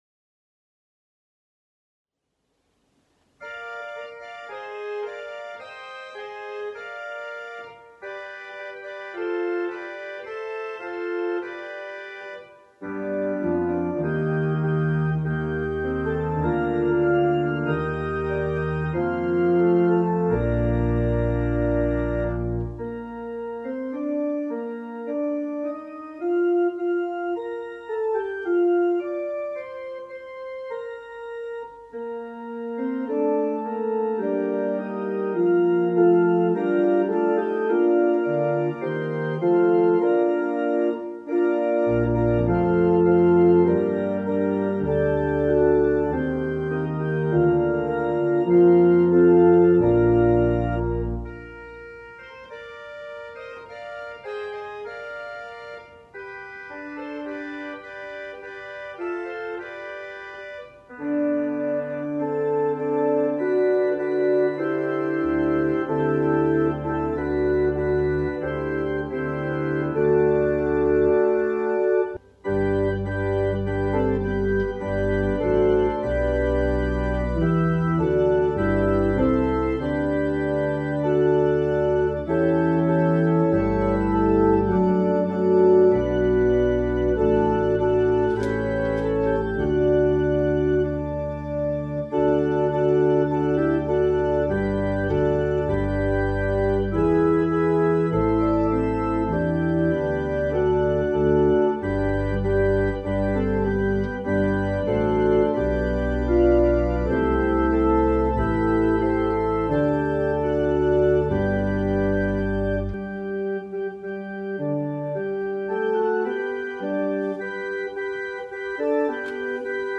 We held virtual worship on Sunday, April 4, 2021 at 10:00am!